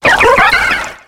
Cri de Keldeo Aspect Normal dans Pokémon X et Y.